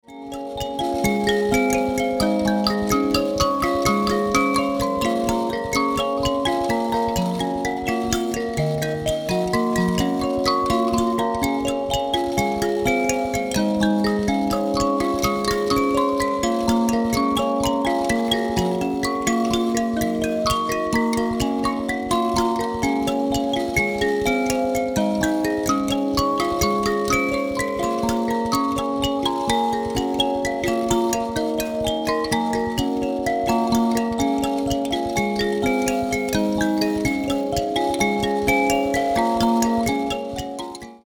it was recorded in an incredible recording studio
katsanzaira tuning